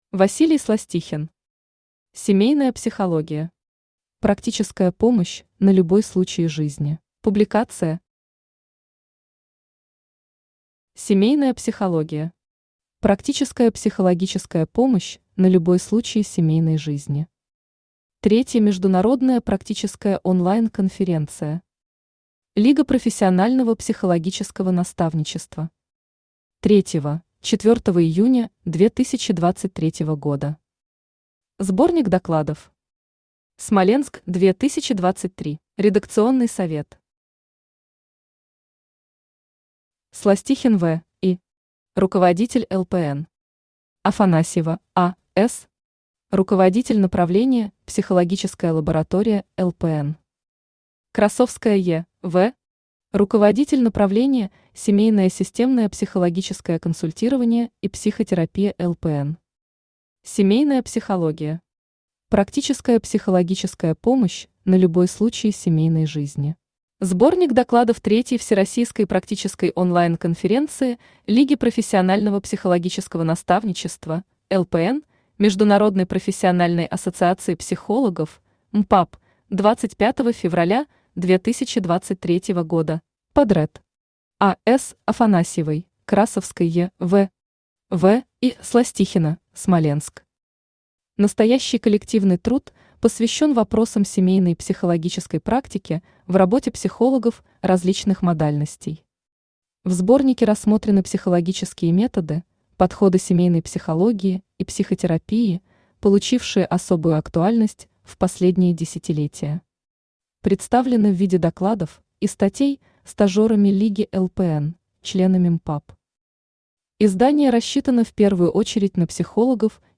Аудиокнига Семейная Психология. Практическая помощь на любой случай жизни | Библиотека аудиокниг
Практическая помощь на любой случай жизни Автор Василий Иванович Сластихин Читает аудиокнигу Авточтец ЛитРес.